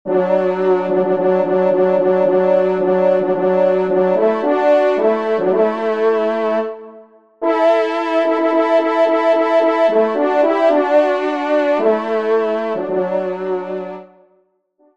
Genre : Musique Religieuse pour  Quatre Trompes ou Cors
Pupitre 2°Trompe